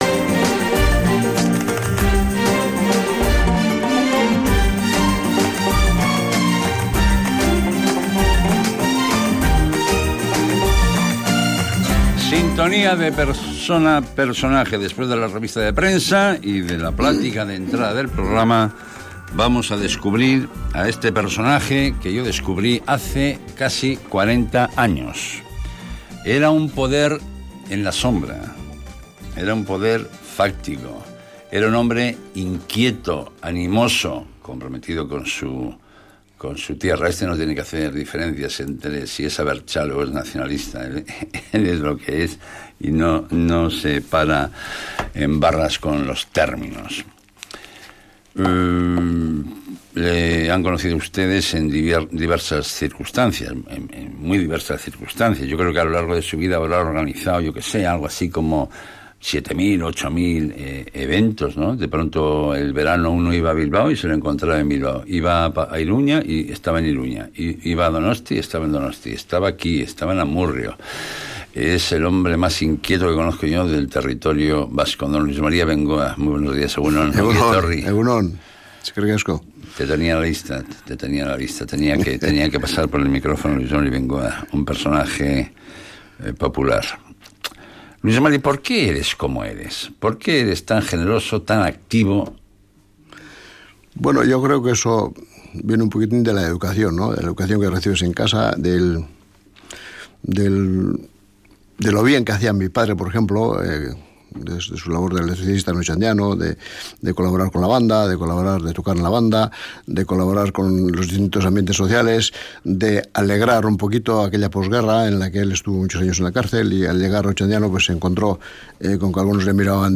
Entrevista
No sólo nos habla de su vida sino que también nos ha tocado la trompeta